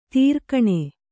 ♪ tīrkaṇe